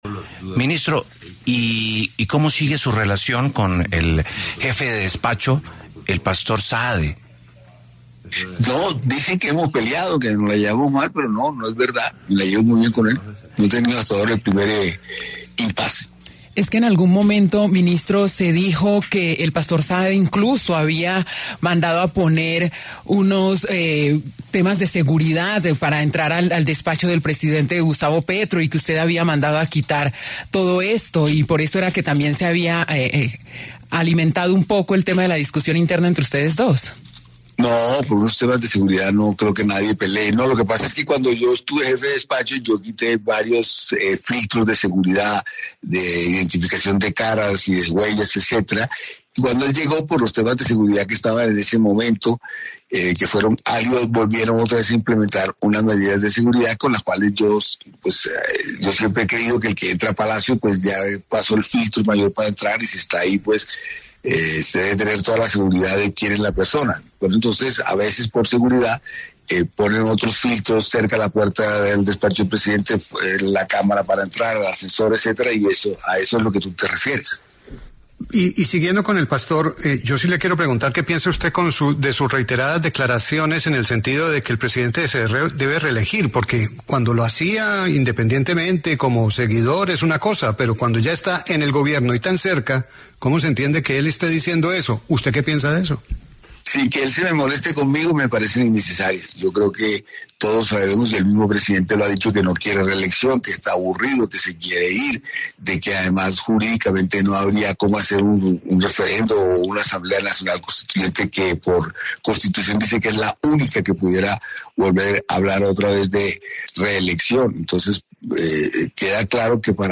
El ministro del Interior, Armando Benedetti, habló en los micrófonos de La W, con Julio Sánchez Cristo, sobre su relación con Alfredo Saade, jefe del Despacho del presidente Gustavo Petro, ya que dentro de la opinión pública se ha mencionado que han tenido diferencias.